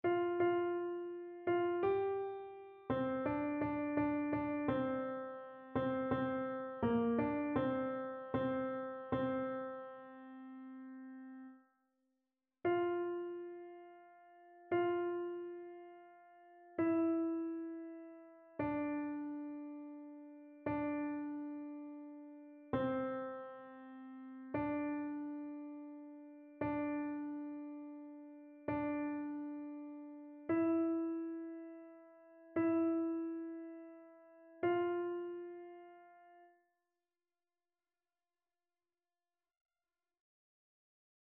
annee-c-temps-pascal-5e-dimanche-psaume-144-alto.mp3